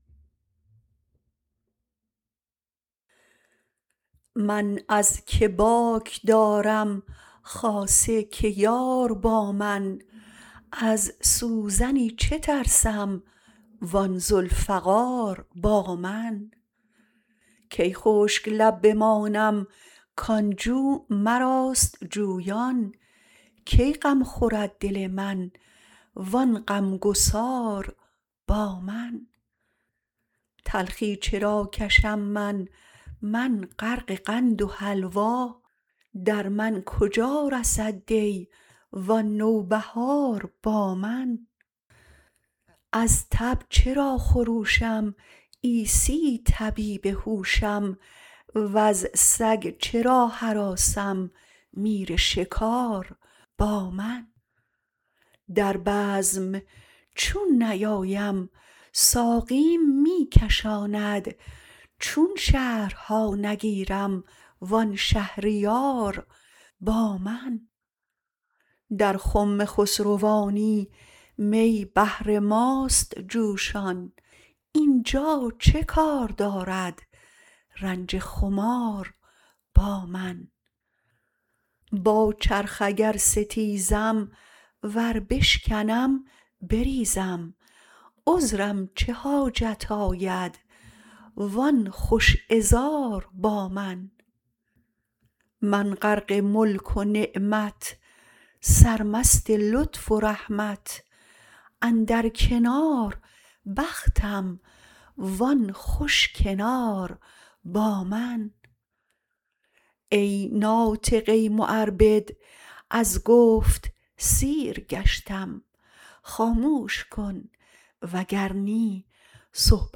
مولانا » دیوان شمس » غزلیات » غزل شمارهٔ ۲۰۳۲ با خوانش